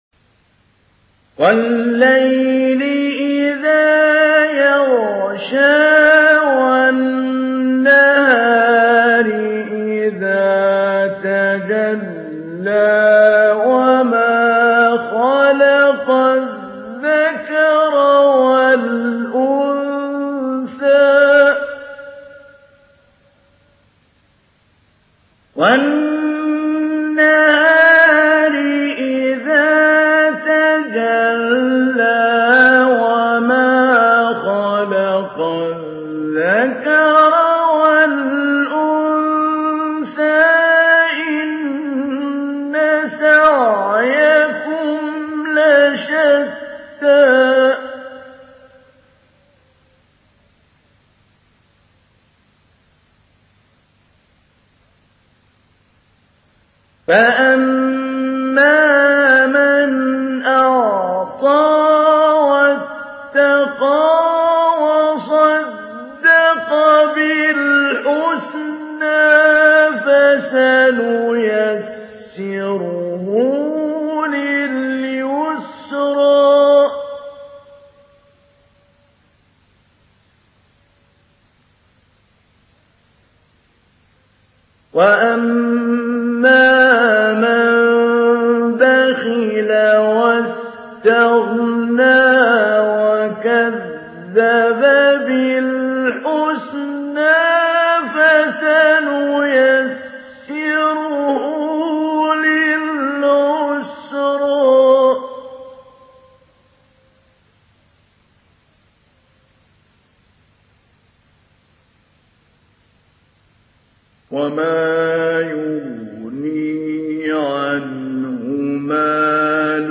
Surah Leyl MP3 in the Voice of Mahmoud Ali Albanna Mujawwad in Hafs Narration
Surah Leyl MP3 by Mahmoud Ali Albanna Mujawwad in Hafs An Asim narration.